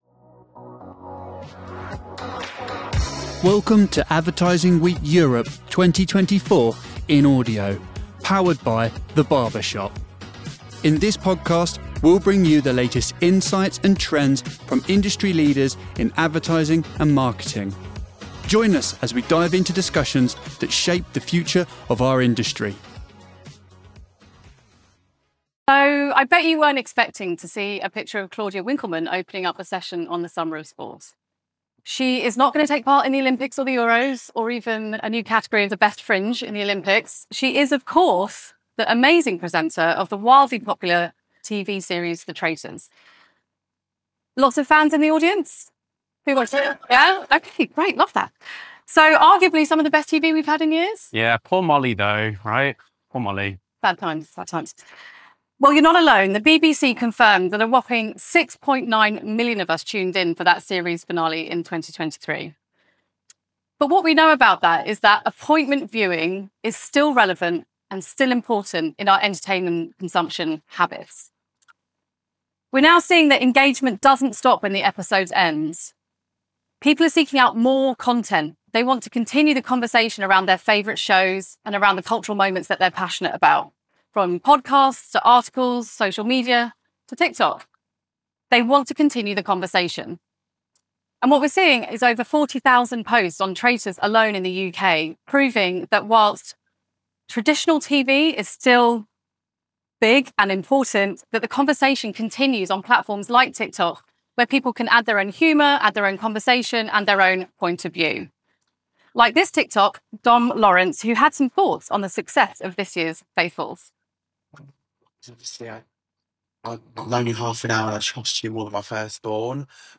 Discover how TikTok is revolutionizing cultural moments in sports, offering a platform for fans to connect and share experiences like never before. This session showcases TikTok's unique approach to sports engagement, transforming the way events like the Olympics are viewed and interacted with globally.